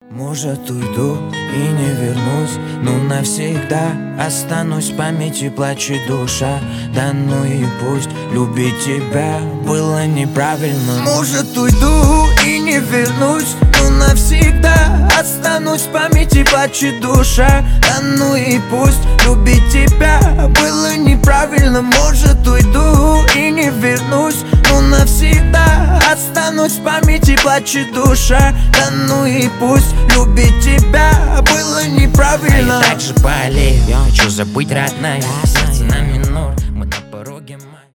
лирика
рэп